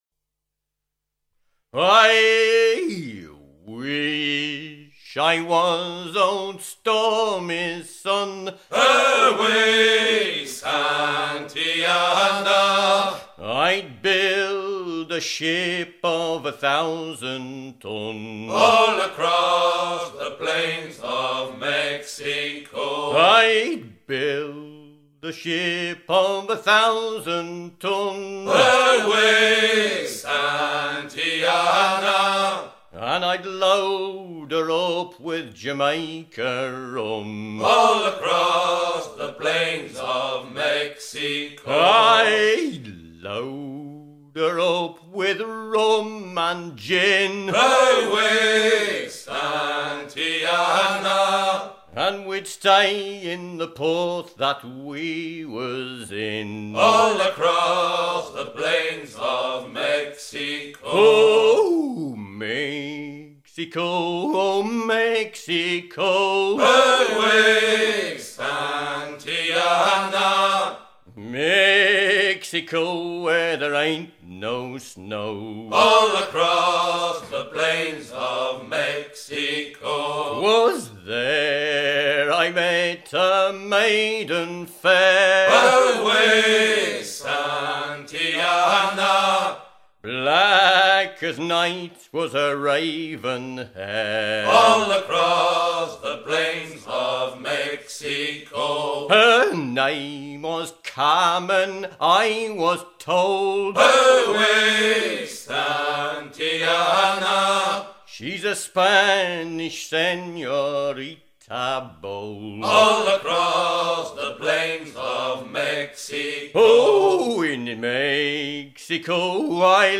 Chantey